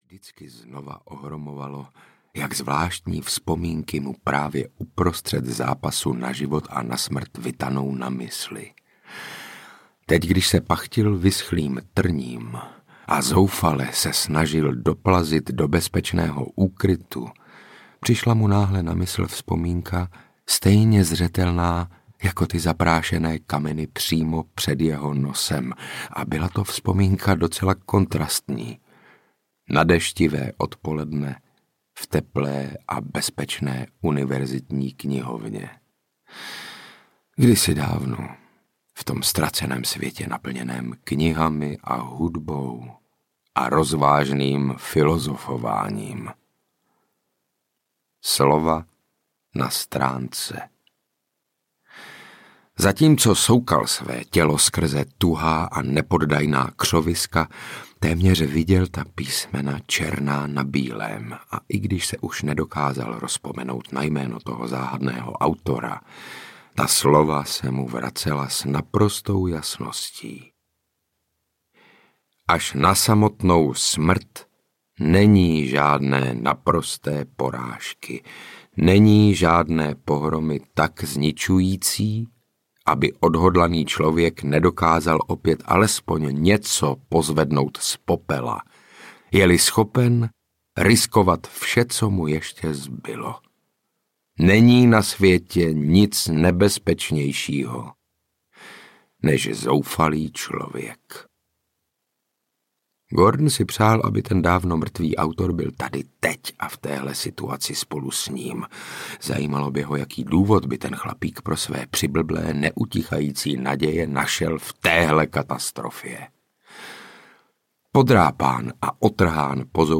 Pošťák audiokniha
Ukázka z knihy
• InterpretSaša Rašilov ml.